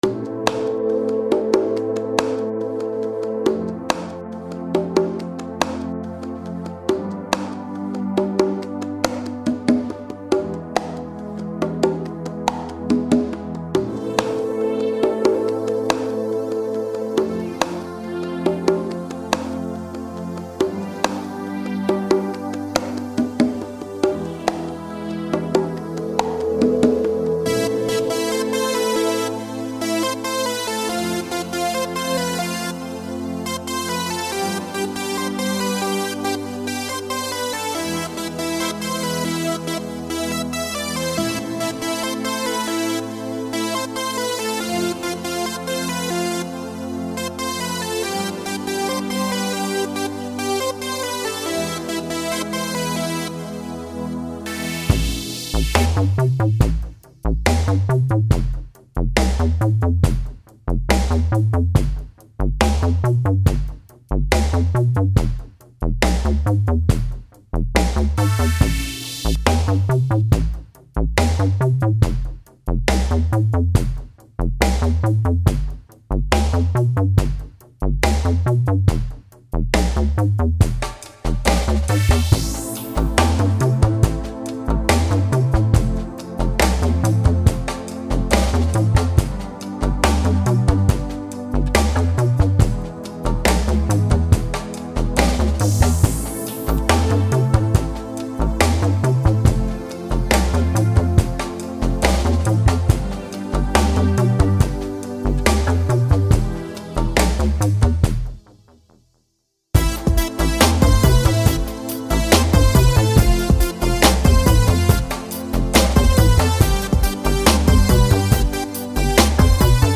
Поп-музыка
• Жанр песни: Жанры / Поп-музыка